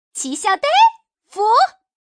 Index of /poker_paodekuai/update/1527/res/sfx/changsha_woman/